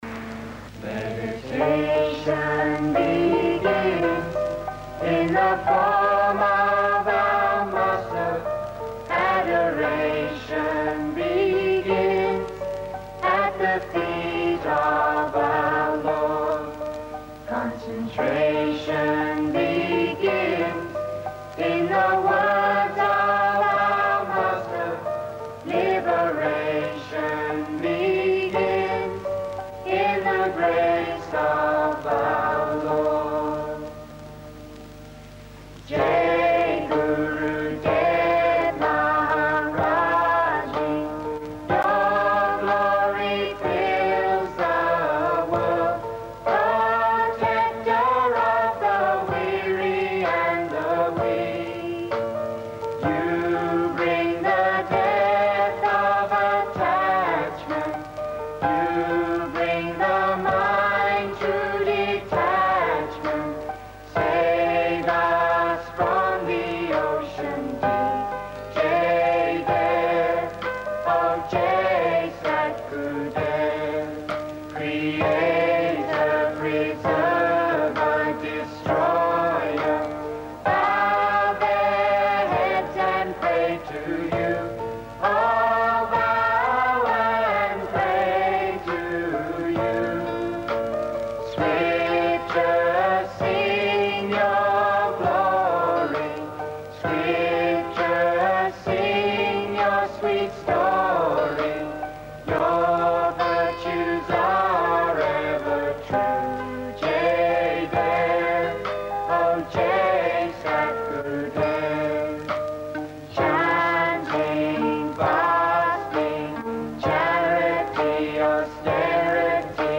Arti is a Hindu ceremony of worship which was sung to Prem Rawat daily for the first decade of his career in the West.
A 1977 Arti Medley - Song of Worship To Prem Rawat As Performed at various Festivals